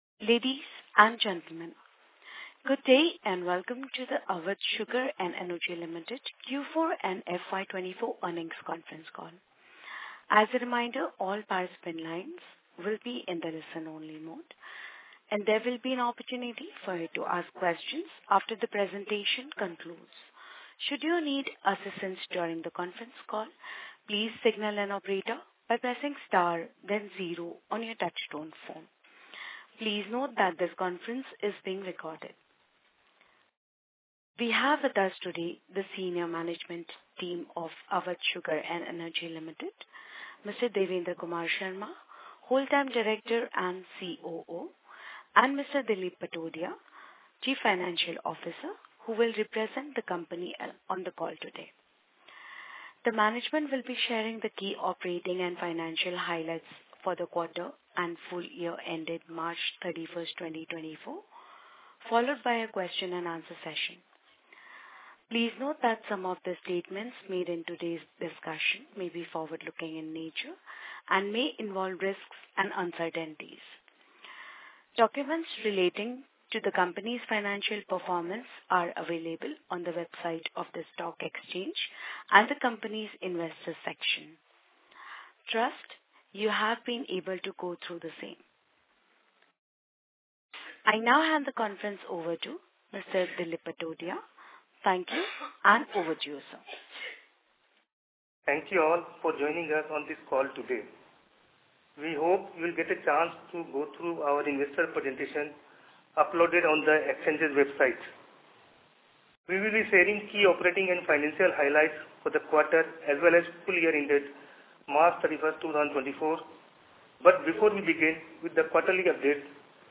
Avadh-Concall-Audio_Q4FY24.mp3